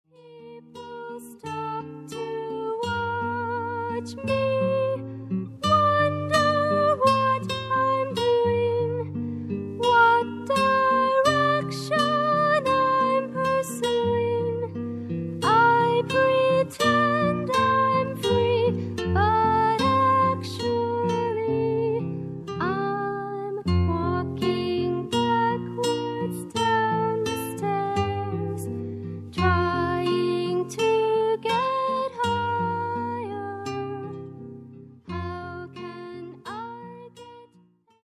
女性一人を含むテキサス出身の4人組